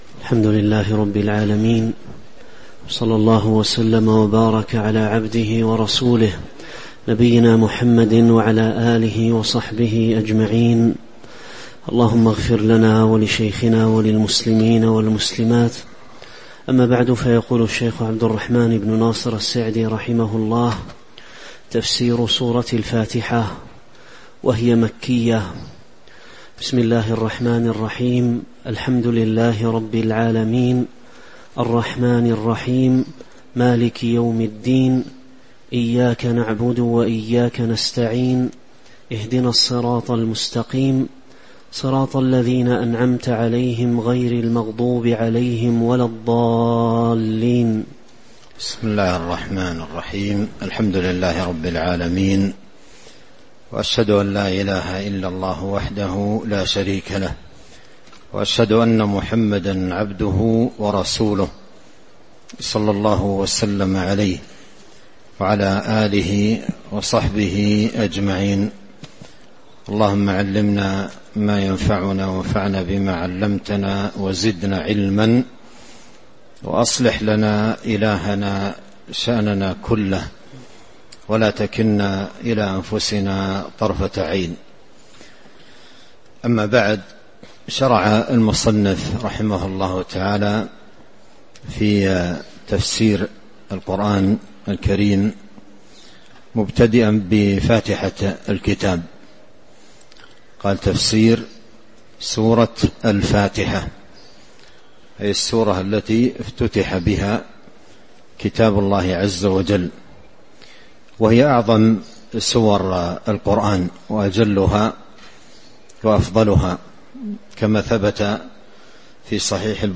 تاريخ النشر ١٣ ربيع الأول ١٤٤٦ هـ المكان: المسجد النبوي الشيخ: فضيلة الشيخ عبد الرزاق بن عبد المحسن البدر فضيلة الشيخ عبد الرزاق بن عبد المحسن البدر تفسير سورة الفاتحة من آية 01-07 (01)